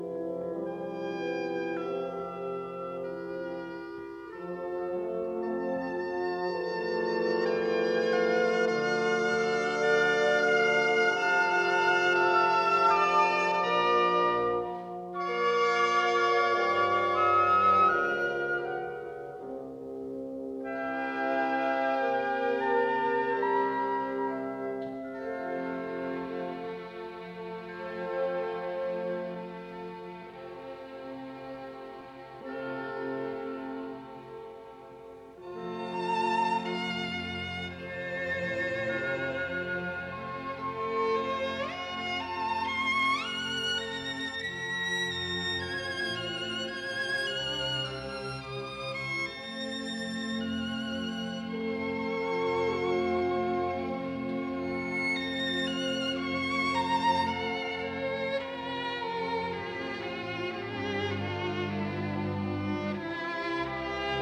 Adagio
oboe